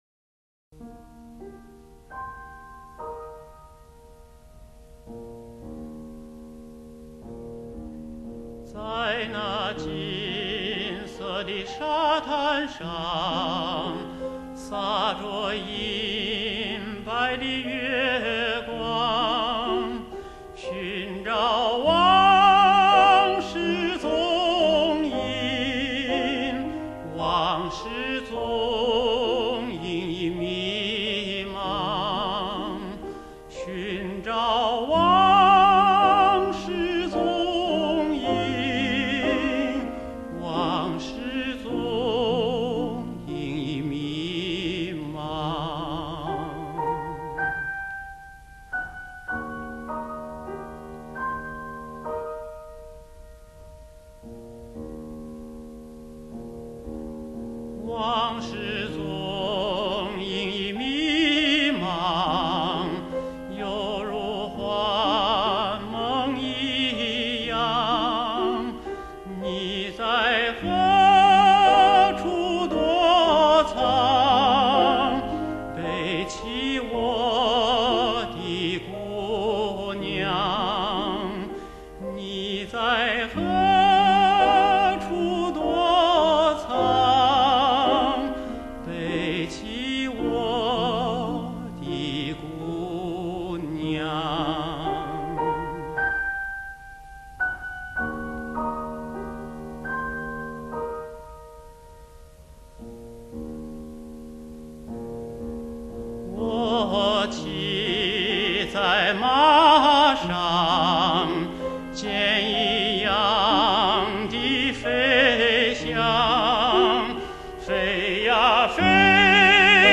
新疆民歌改编